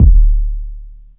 • Classic Nice Steel Kick Drum Sample D Key 03.wav
Royality free bass drum tuned to the D note. Loudest frequency: 55Hz
classic-nice-steel-kick-drum-sample-d-key-03-bw1.wav